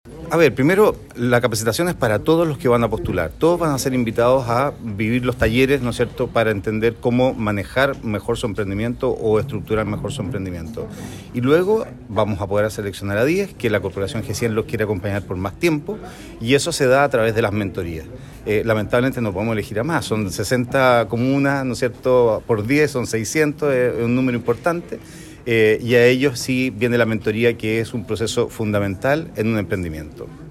En Sala de Sesiones se realizó el lanzamiento de la segunda versión del Concurso de Emprendimiento “Nada Nos Detiene”, que ejecuta la Corporación G-100, con la colaboración del municipio de Osorno y el patrocinio del “Grupo de Empresas Feria Osorno”, y que premiará con $3 millones de pesos al ganador.